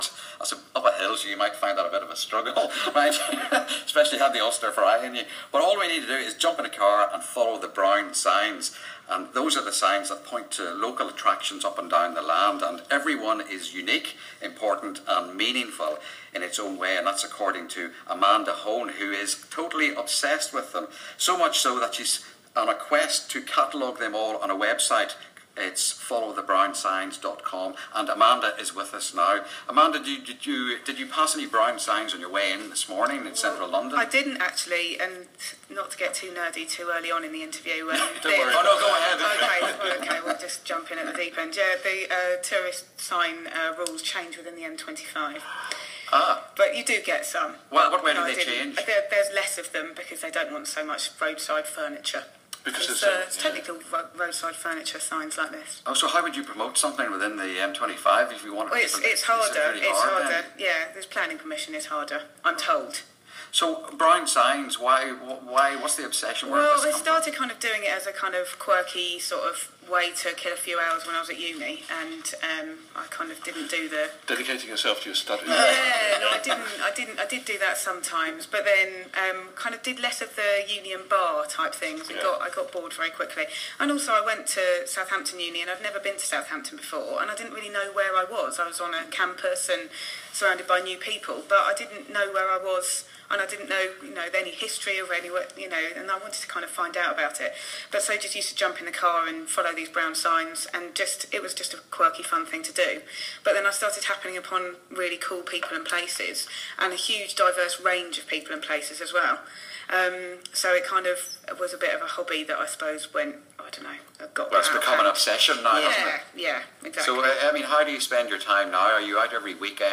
My interview is here: